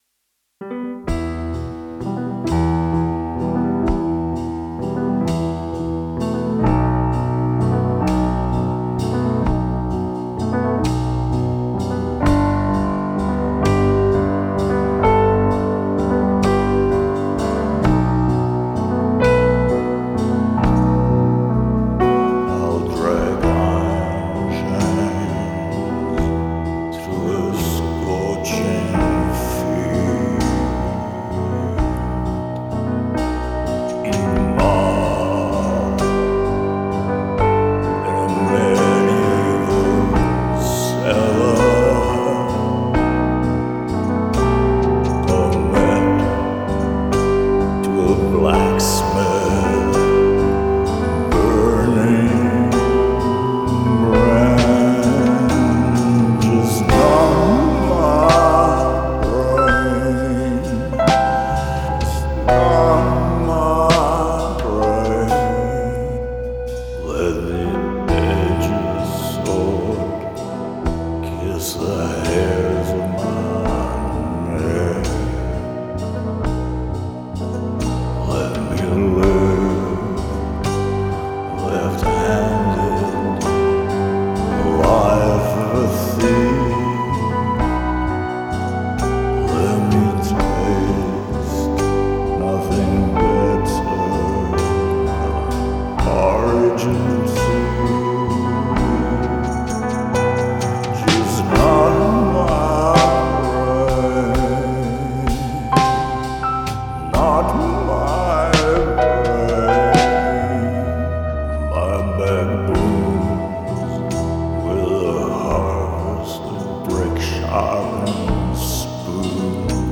Probably my favorite of the year revisiting the Waldorf M.